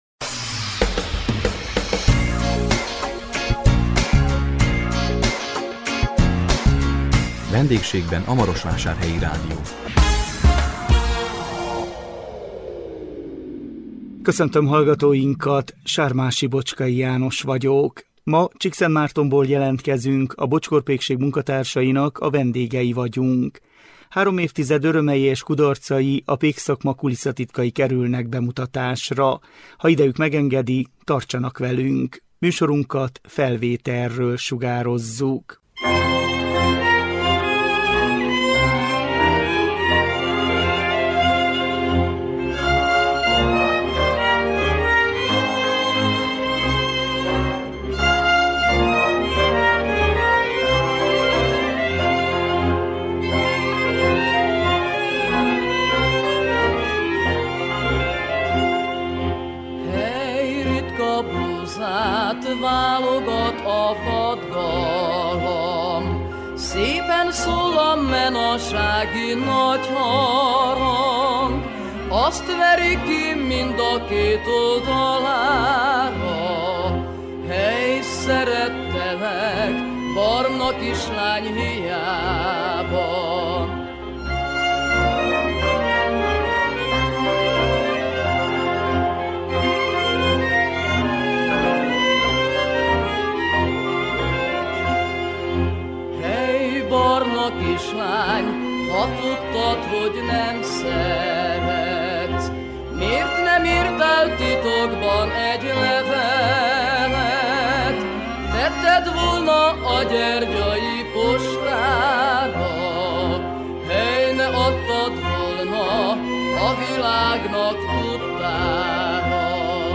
A 2026 április 9-én közvetített VENDÉGSÉGBEN A MAROSVÁSÁRHELYI RÁDIÓ című műsorunkkal Csíkszentmártonból jelentkezünk, a Bocskor Pékség munkatársainak a vendégei voltunk. Három évtized örömei és kudarcai, a pékszakma kulisszatitkai kerültek bemutatásra.